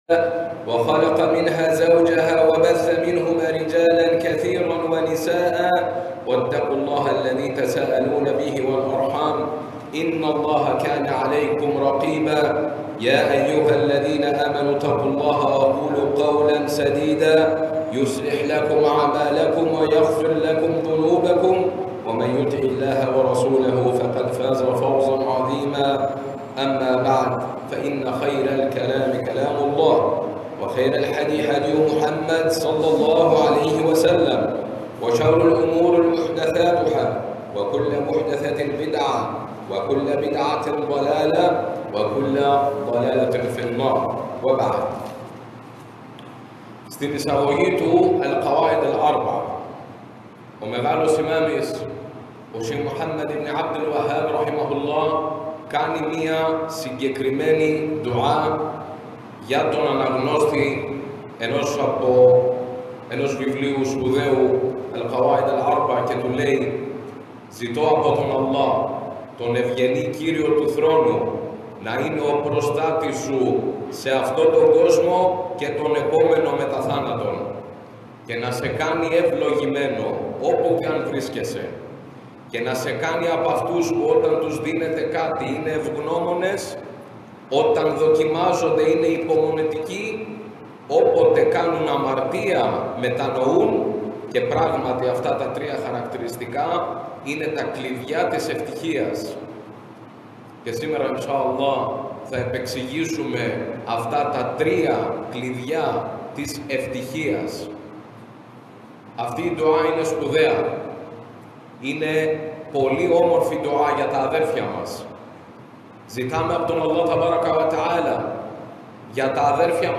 Το κήρυγμα της παρασκευής: Εκεί όπου η ψυχή αναπαύεται – خطبة الجمعة – طريق السعادة في الدنيا والآخرة